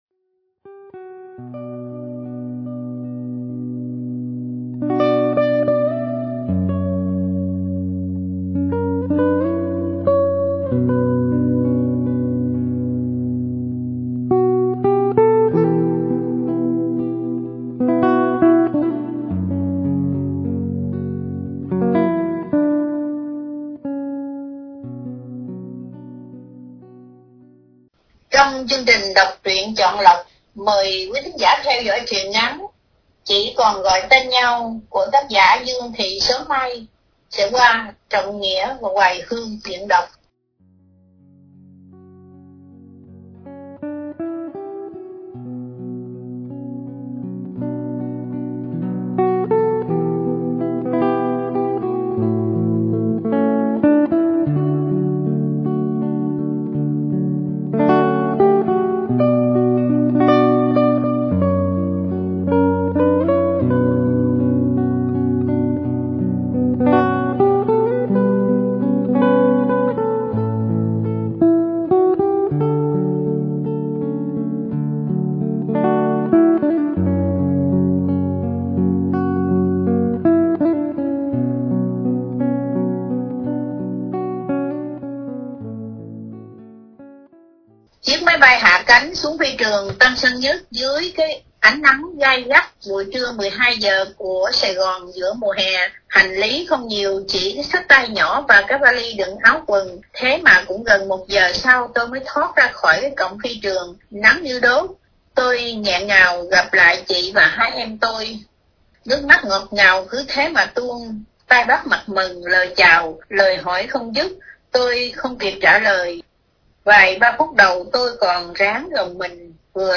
Đọc Truyện Chọn Lọc – Truyện Ngắn “Chỉ Còn Gọi Tên Nhau “- Dương Thị Sớm Mai.